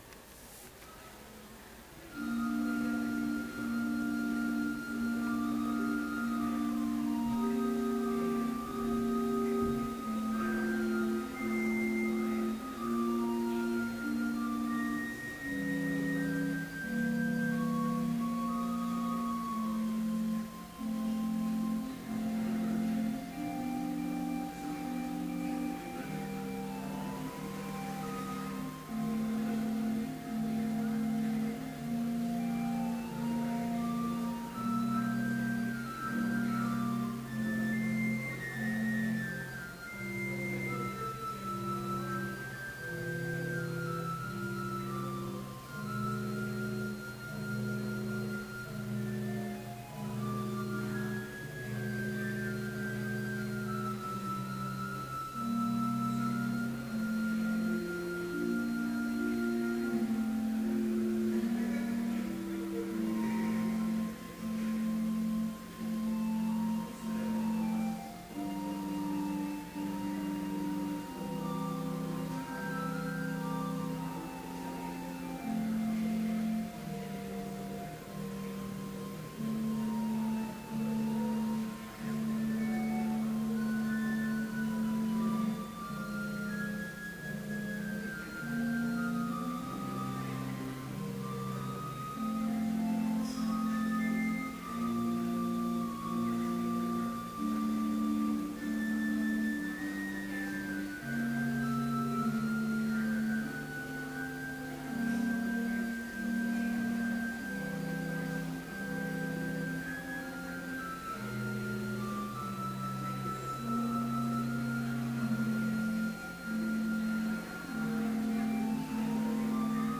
Complete service audio for Chapel - April 18, 2013